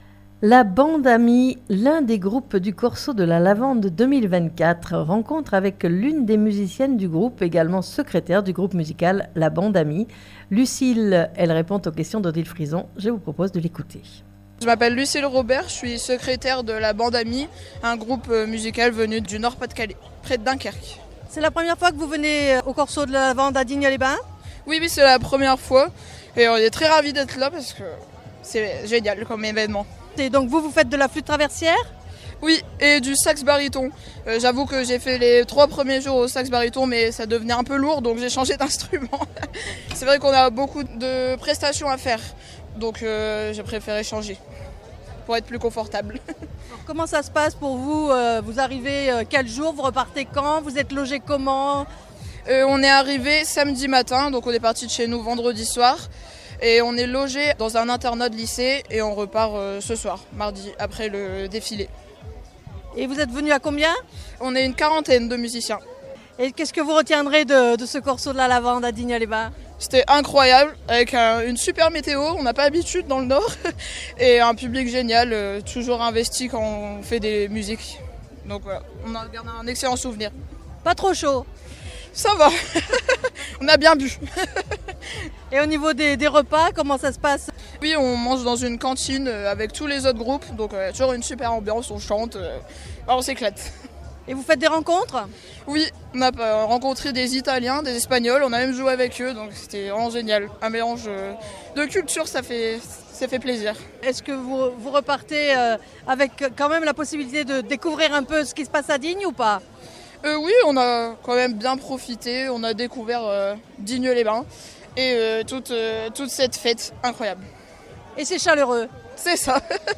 La Band'amis - l'un des groupes musicaux du Corso de la Lavande 2024 : Mardi 6 Aout 2024 Rencontre avec l'une des musiciennes du groupe